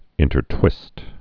(ĭntər-twĭst)